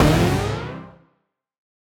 Index of /musicradar/future-rave-samples/Poly Chord Hits/Ramp Up